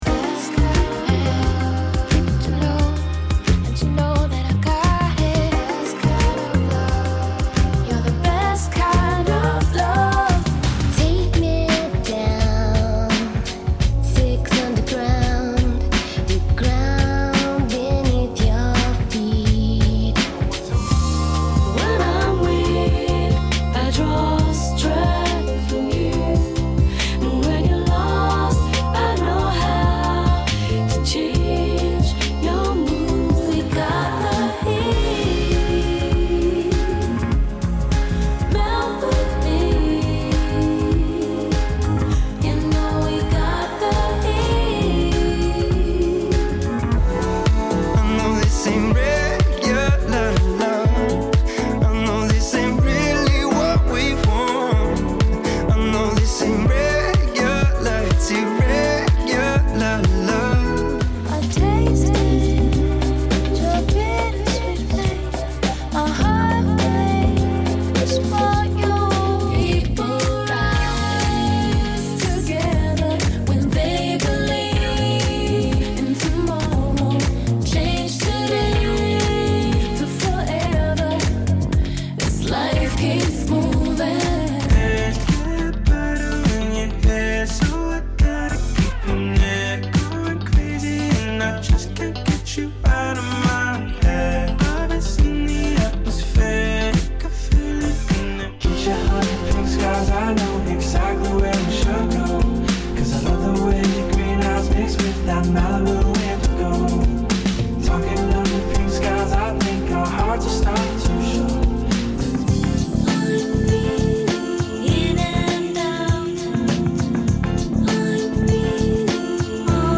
Relaxed Tempo